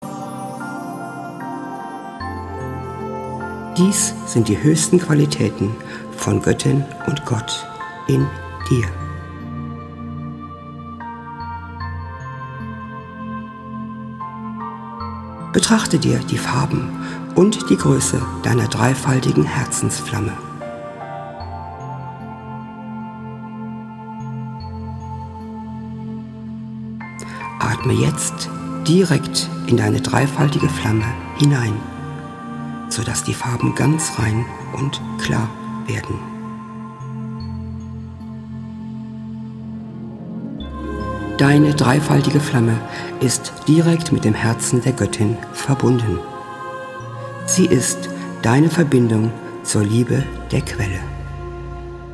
Diese Meditationen, untermalt mit traumhaft schöner Musik, können dich ganz tief zu deinem wahren Selbst führen.